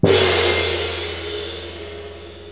Gong Bass Sound Effect Free Download
Gong Bass